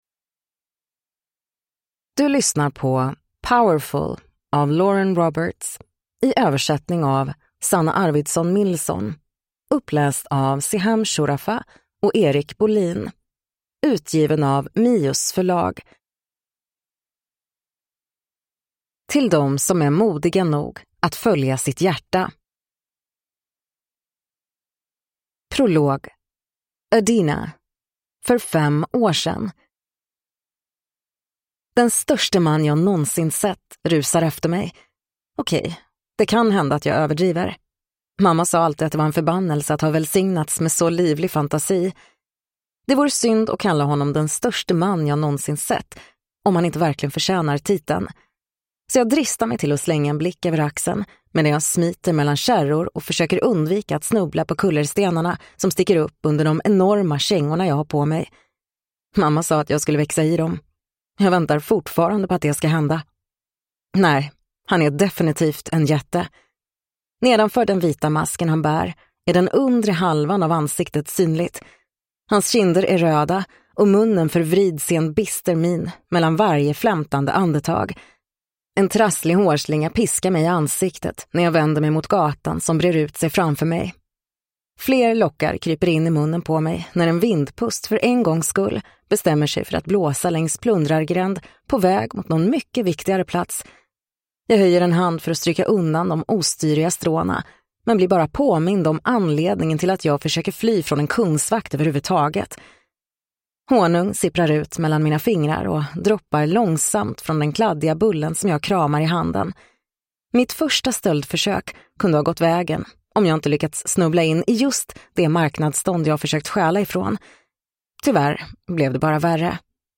Powerful (svensk utgåva) – Ljudbok